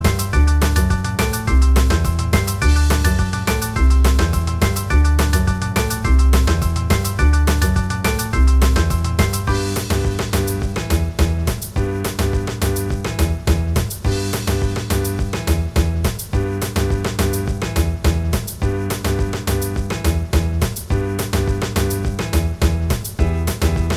Minus Guitars Pop (1960s) 2:11 Buy £1.50